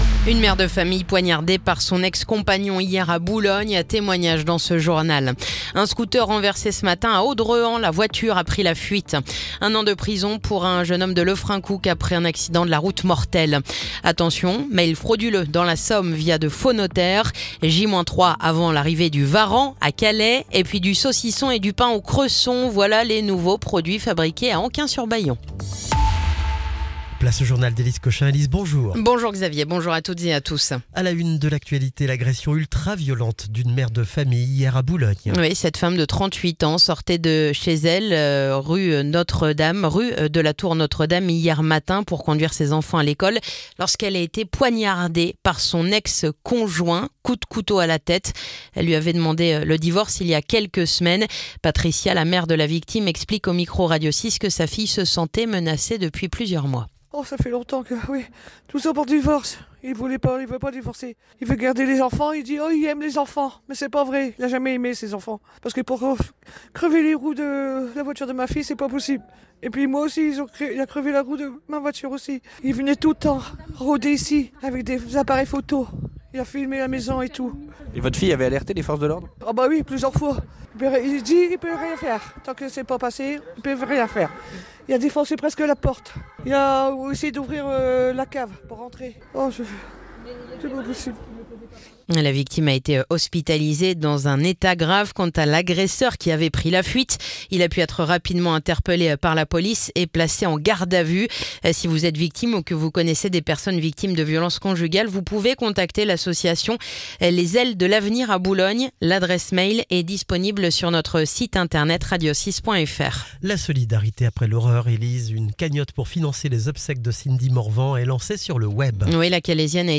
Le journal du mardi 4 novembre